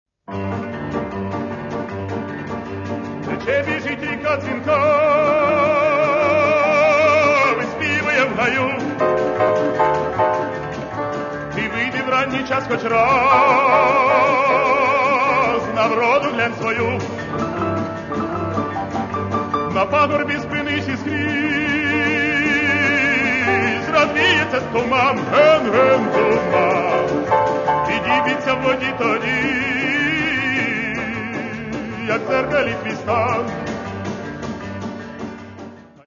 Каталог -> Эстрада -> Певцы